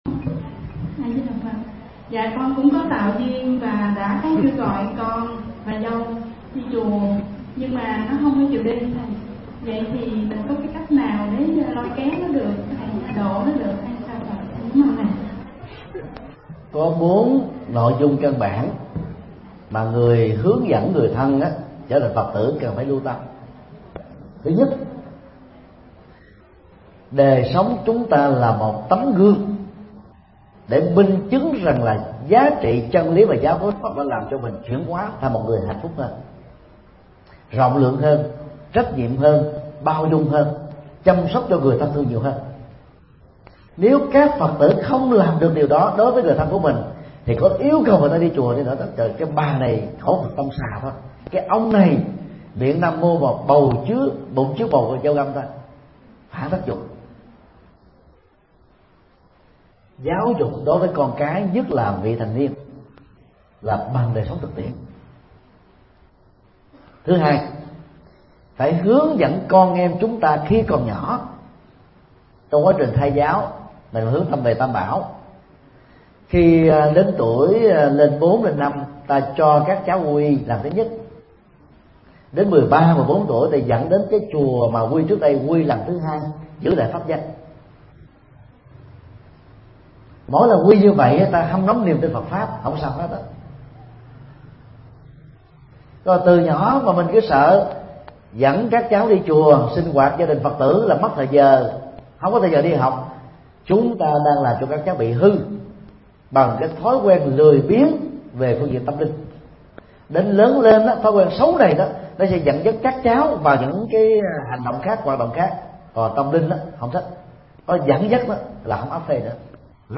Nghe mp3 Vấn đáp: Những điều cần biết để hướng dẫn người thân thành Phật tử